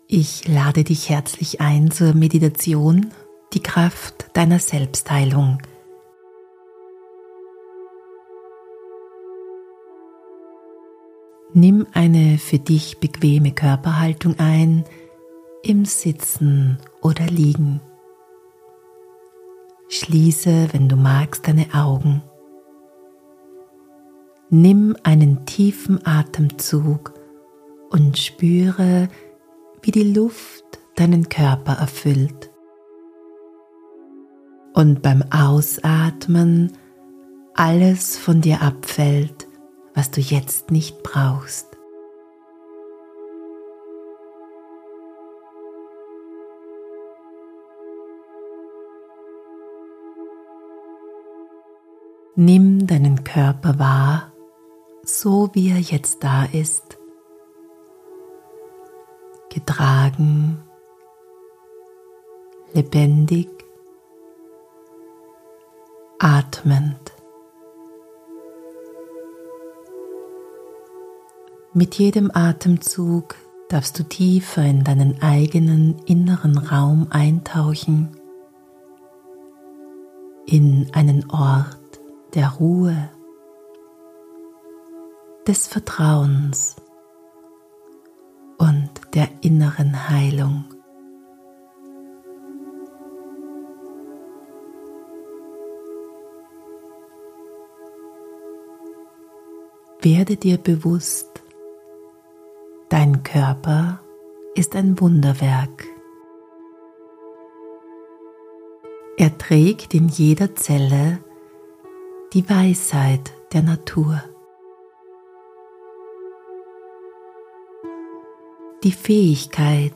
Diese Meditation unterstützt dich dabei, Vertrauen in diese Kraft zu entwickeln, dich selbst liebevoll anzunehmen und dich wieder als Teil der Natur zu spüren. Du wirst durch sanfte innere Bilder, kraftvolle Affirmationen und heilsame Worte begleitet, die deine Selbstheilung aktivieren und dir Momente von Ruhe, innerem Frieden und Zuversicht schenken.